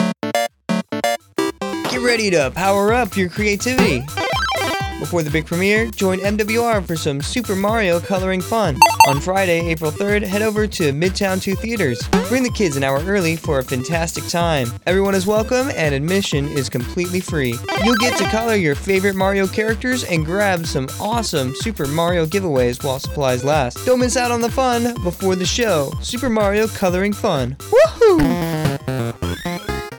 NAVAL AIR STATION SIGONELLA, Italy (March 16, 2026) Radio spot promotes upcoming Super Mario Coloring Fun for Morale, Welfare and Recreation (MWR) Sigonella.